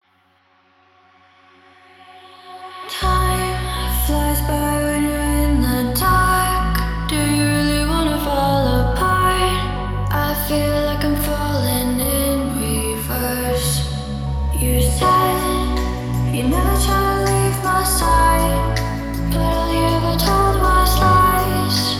Immersive 8D Music Experience